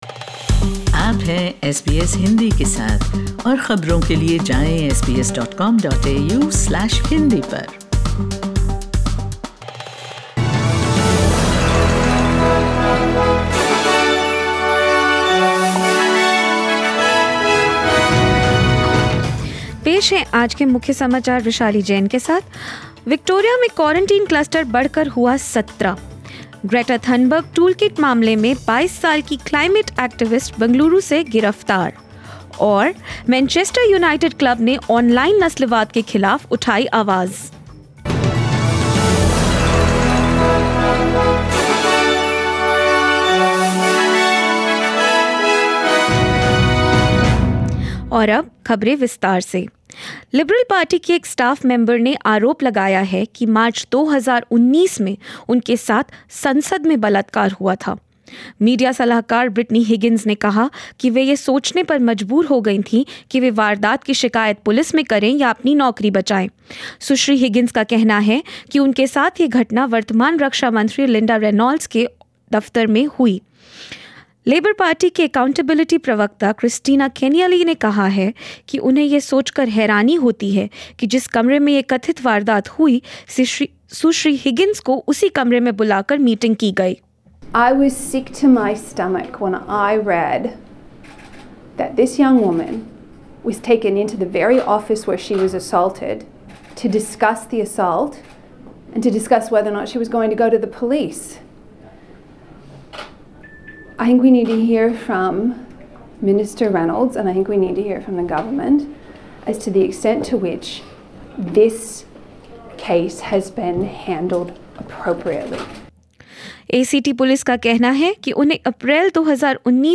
News In Hindi: 22-year-old climate activist from Bengaluru sent in 5-day police custody in India, this and more